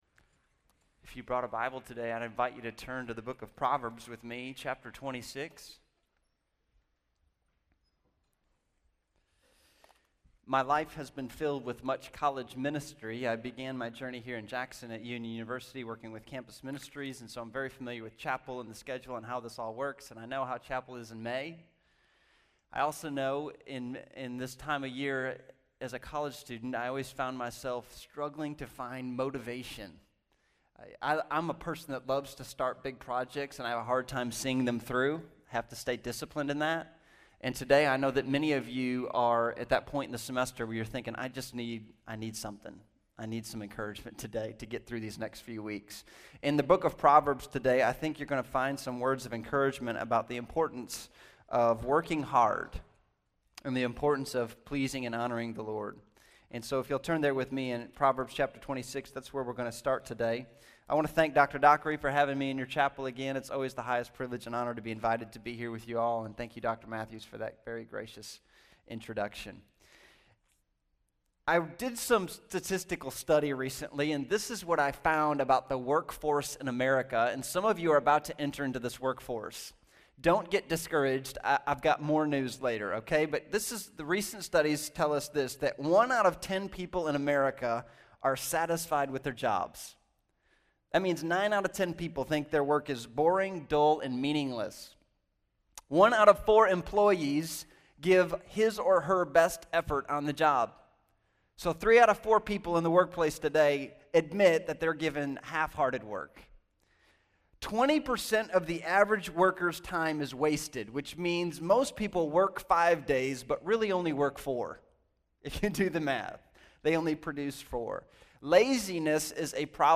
Chapel